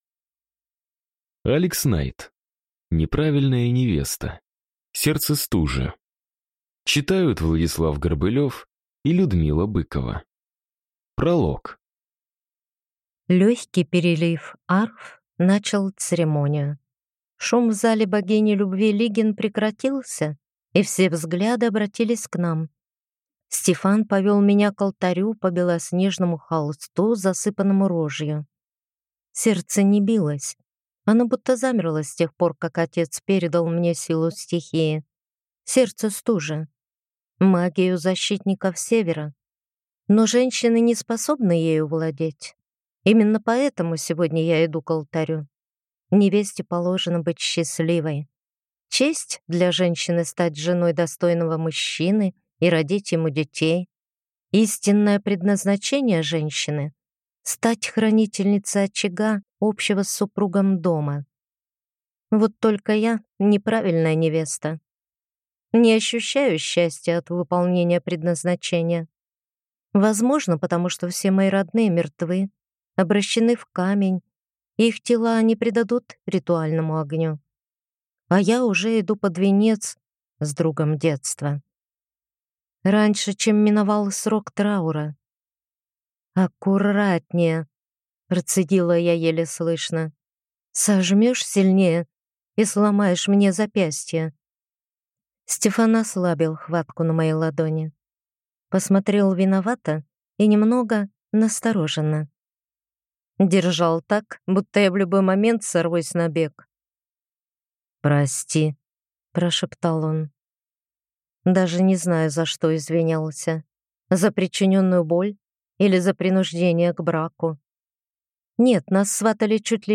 Аудиокнига Неправильная невеста. Сердце Стужи | Библиотека аудиокниг